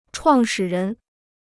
创始人 (chuàng shǐ rén): creator; founder.